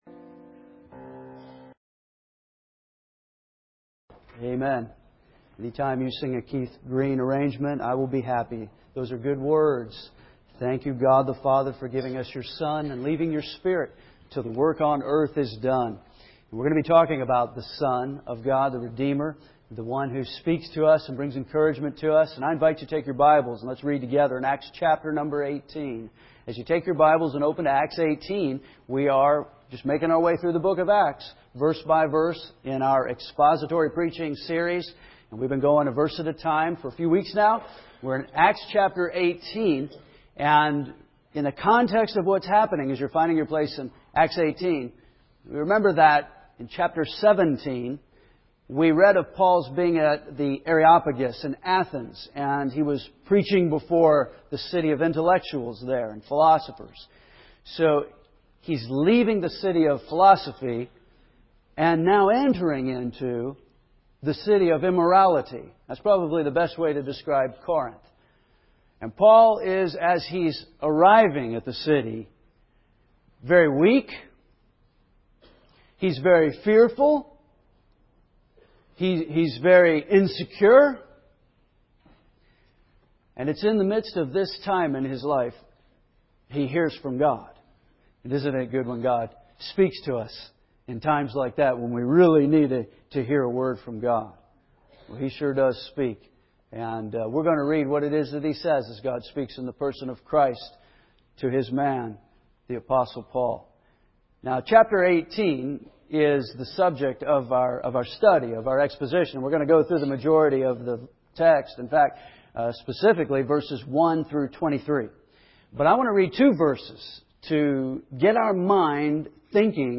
We continue to make our way through the book of Acts in our expository preaching series, verse-by-verse, through an exhilarating book, a study of the church in its early years as it spread throughout the known world.